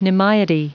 Prononciation du mot nimiety en anglais (fichier audio)
Prononciation du mot : nimiety